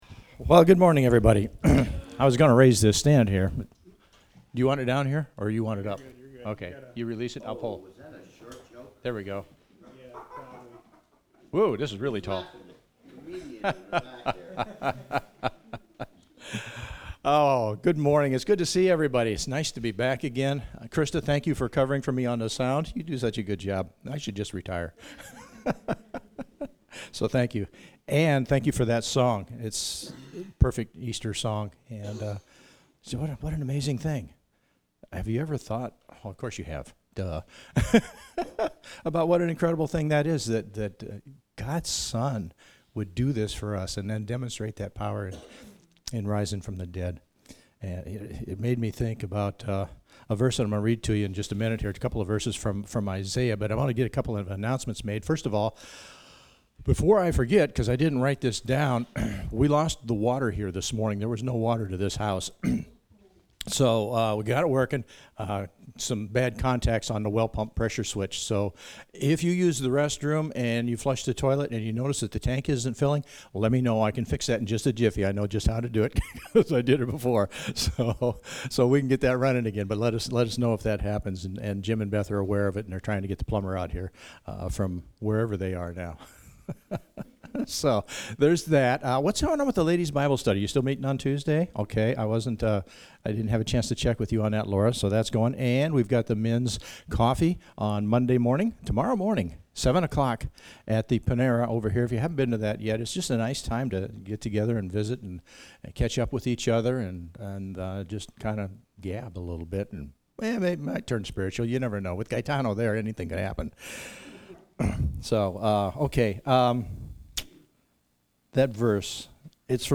Service Type: Gathering